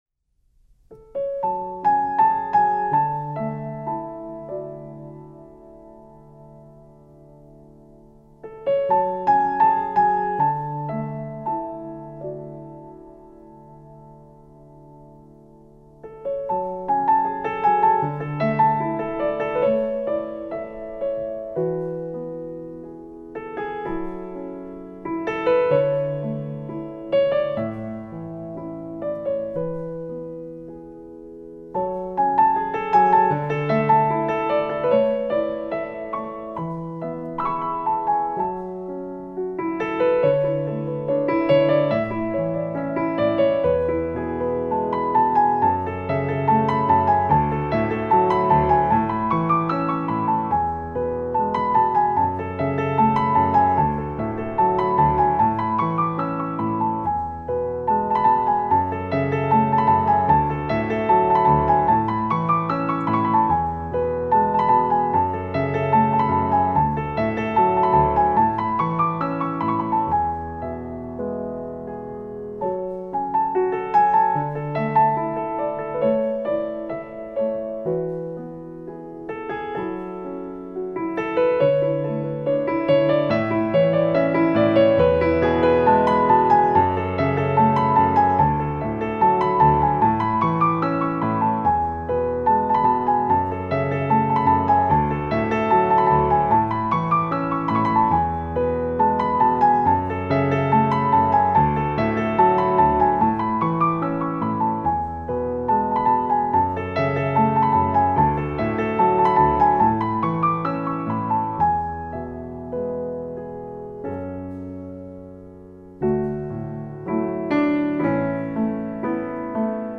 Классическая музыка